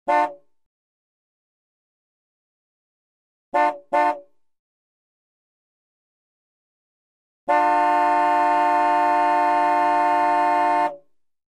Звуки радара